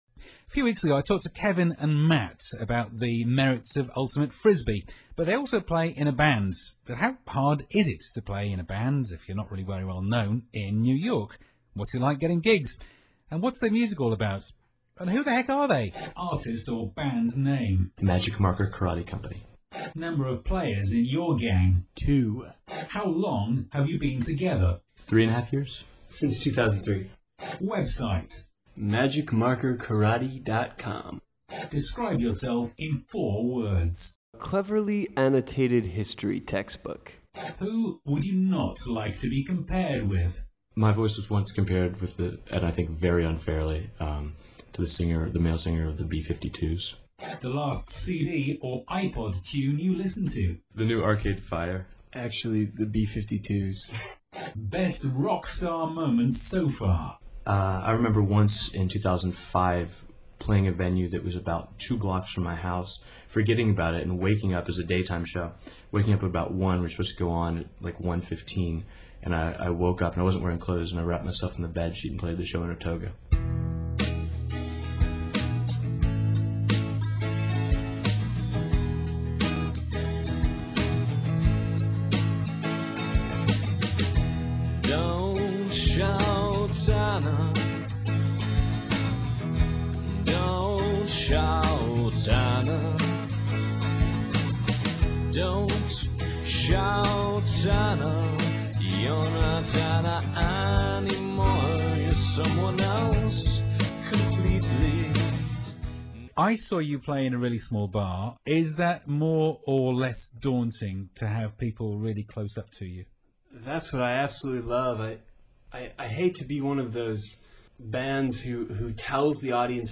interviewed for Hong Kong Radio/Television.